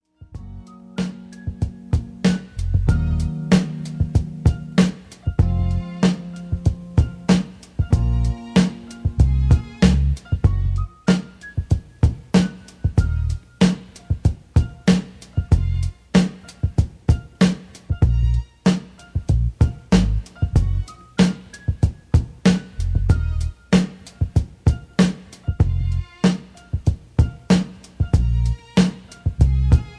R&B over Drumloop. Dance to This